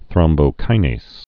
(thrŏmbō-kīnās, -nāz)